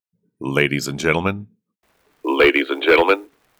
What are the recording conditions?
Try Helmet radio plugin … There’s no tape speed-wobble though, need another plugin for that …